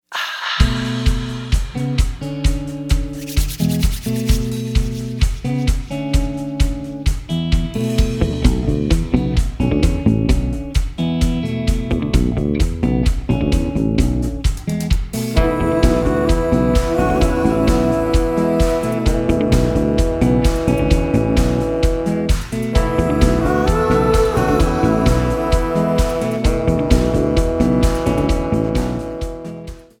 Americana
Rock
Roots